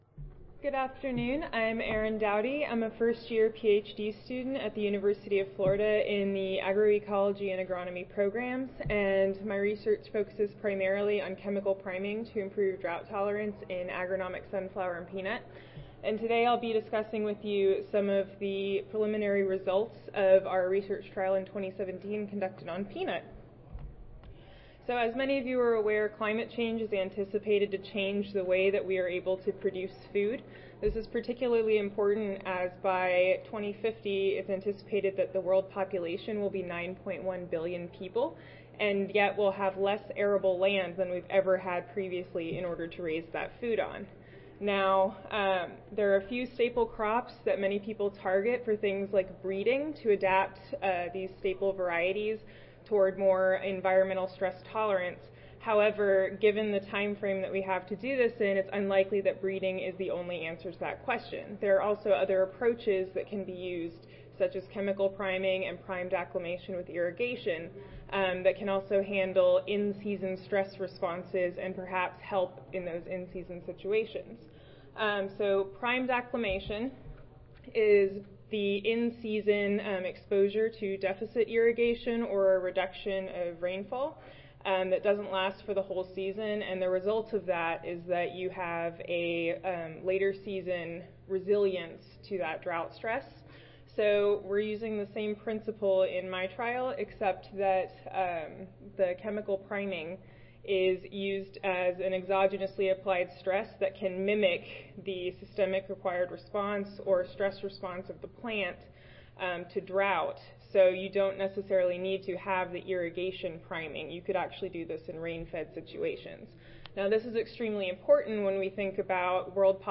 Session: Graduate Student Oral Competition (ASA, CSSA and SSSA International Annual Meetings)
University of Florida Agronomy & Soils Club Audio File Recorded Presentation